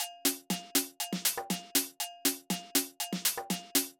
Drumloop 120bpm 02-B.wav